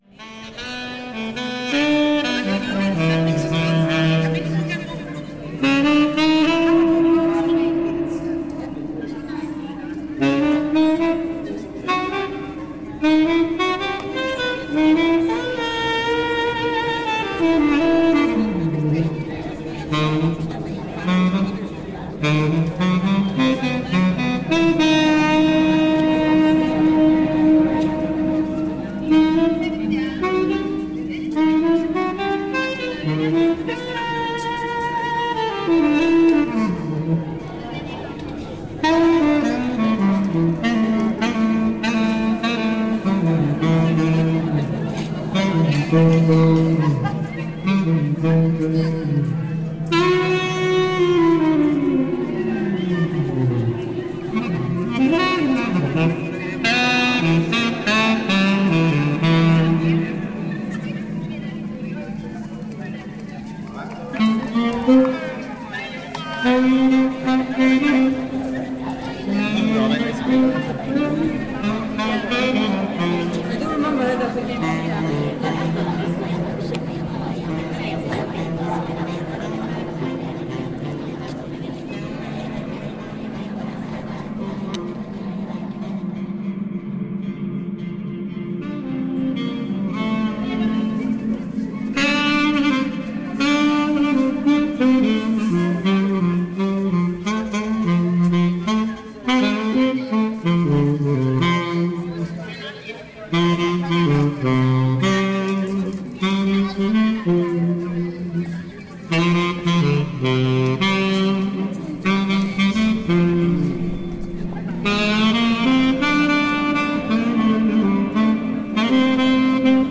The busker of reverb
drowns a busker in reverb